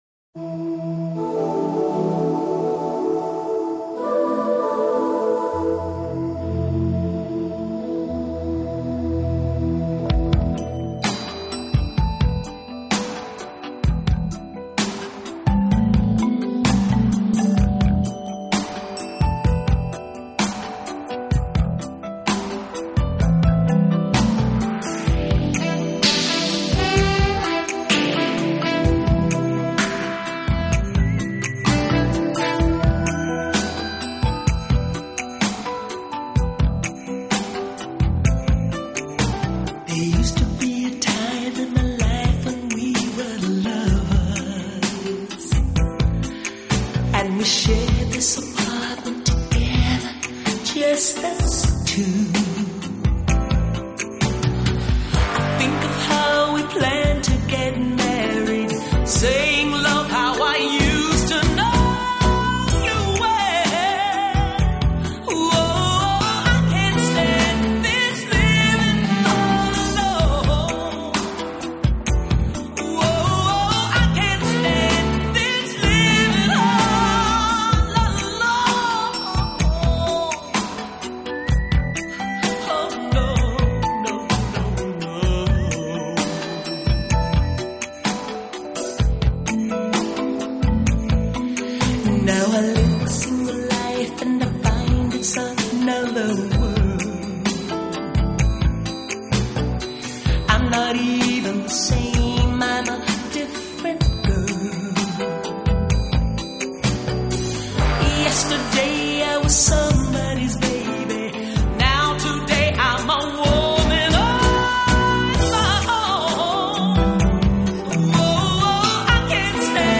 Studio/Live Studio
Mono/Stereo Stereo